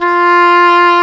Index of /90_sSampleCDs/Roland L-CDX-03 Disk 1/WND_English Horn/WND_Eng Horn 2